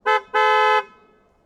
Vehicles, Horn, Car, Audi Q5, Short, Exterior, Multiple SND3345.wav